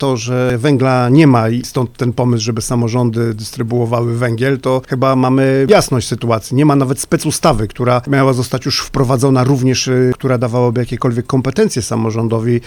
Prezydent Radomia mówi: